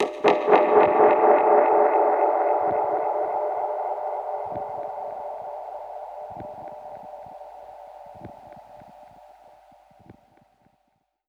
Index of /musicradar/dub-percussion-samples/85bpm
DPFX_PercHit_A_85-01.wav